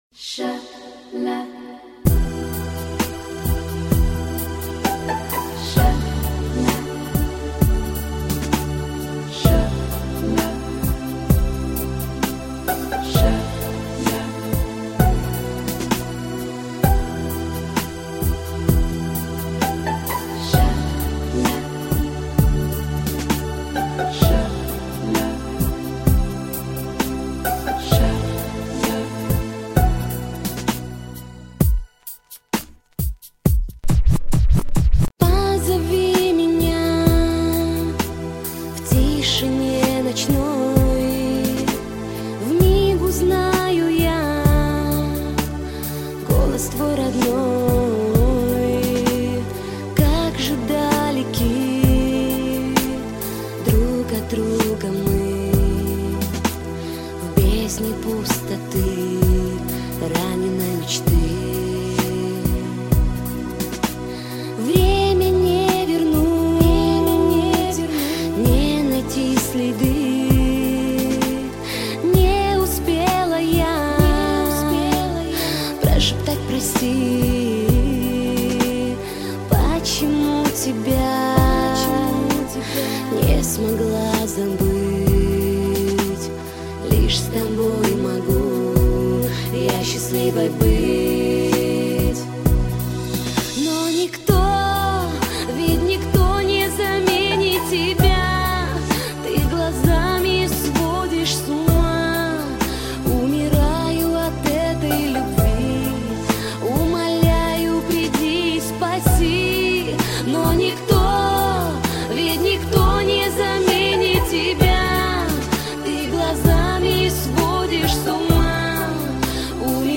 Кавказский попс это!